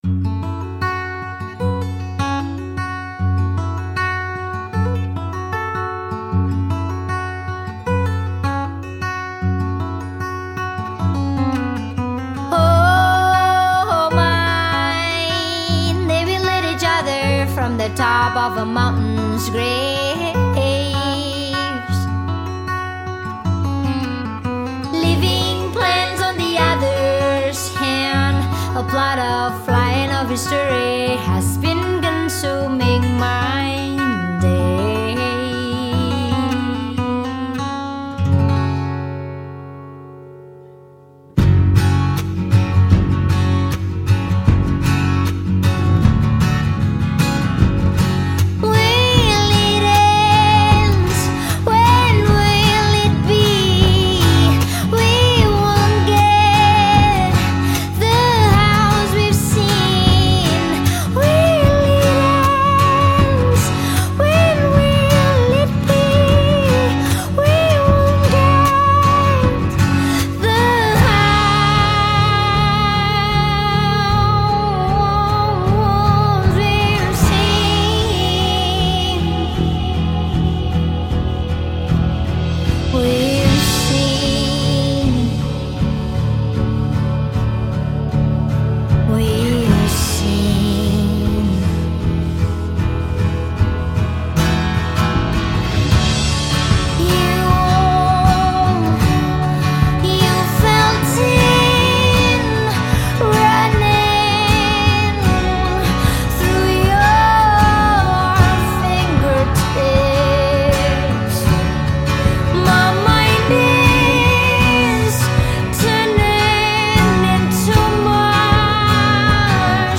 indie-folk tinged track